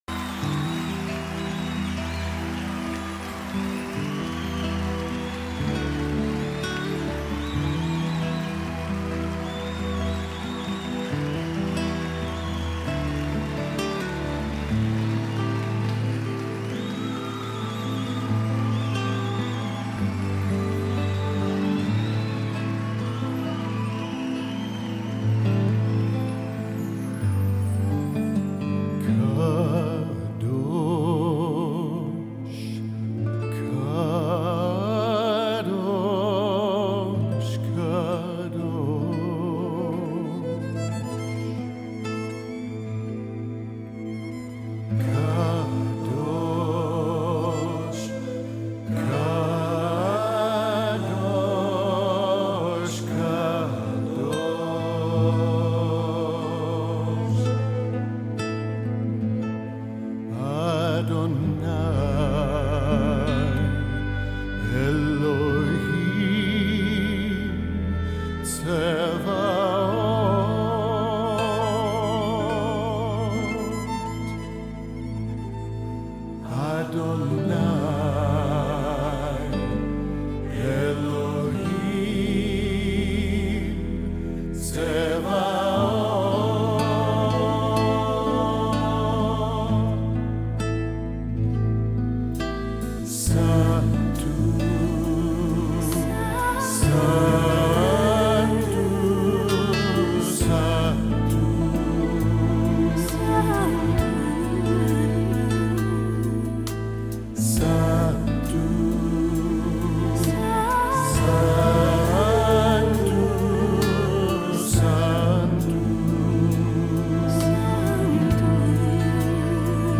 MESSIANIC WORSHIPPER